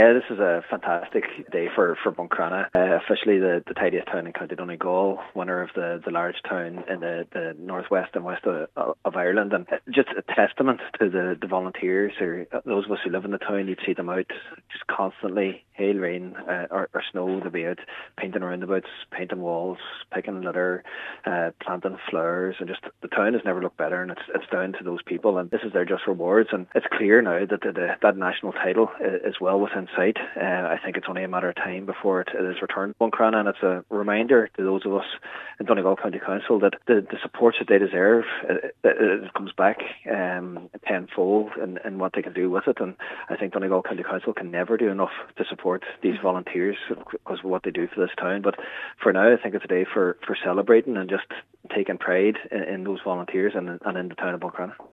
Local Councillor Jack Murray says that today is a day of celebration in the town of Buncrana.